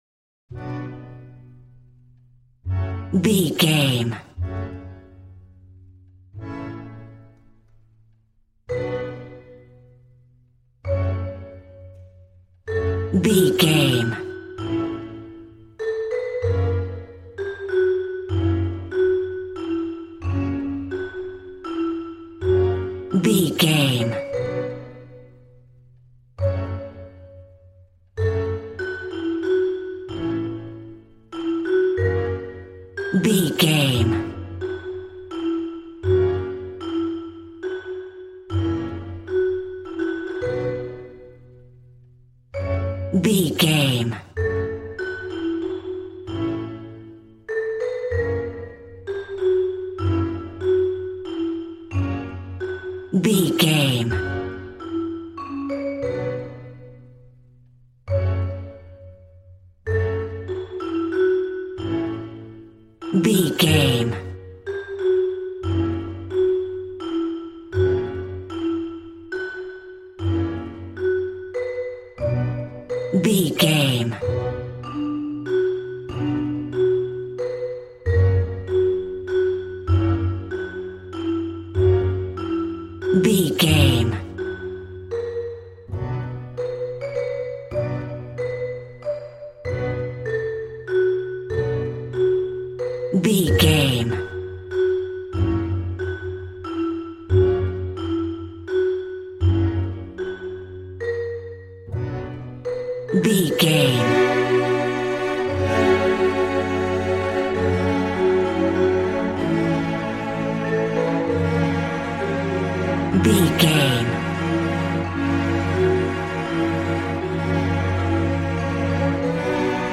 Aeolian/Minor
G♭
cheerful/happy
joyful
drums
acoustic guitar